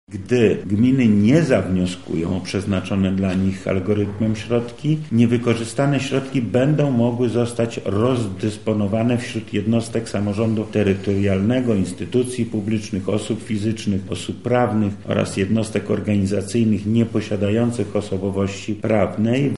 • mówi  wojewoda lubelski Lech Sprawka